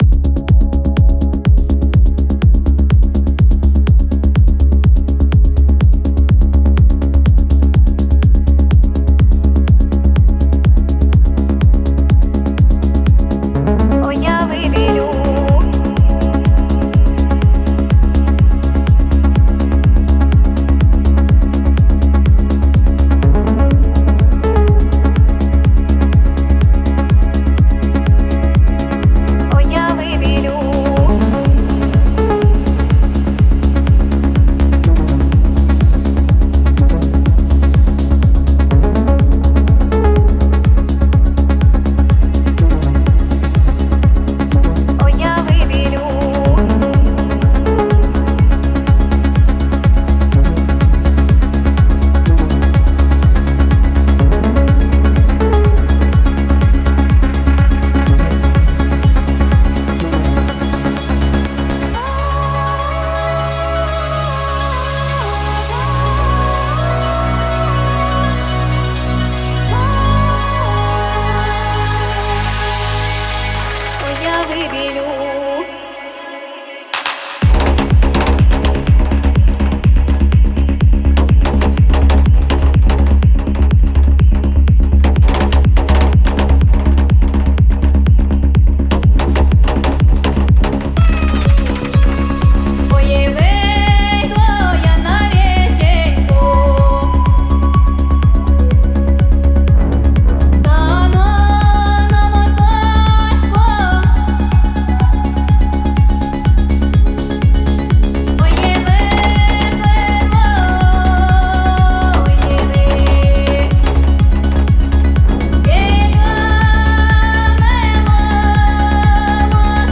• Жанр: EDM, Dance